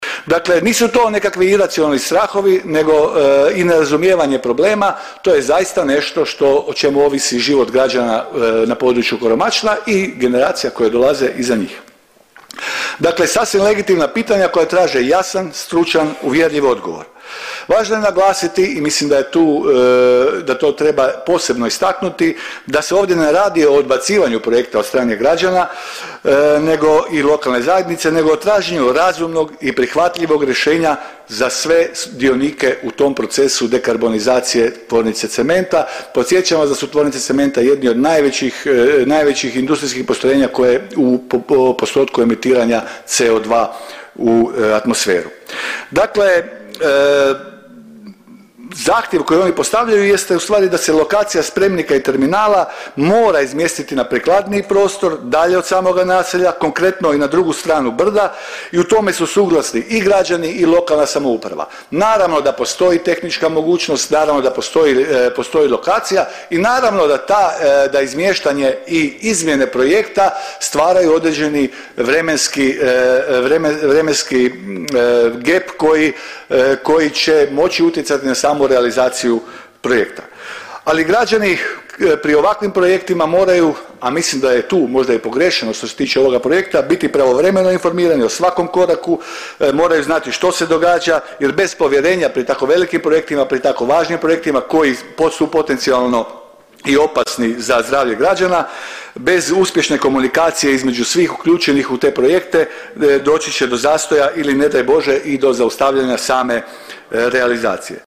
ton – Dalibor Paus).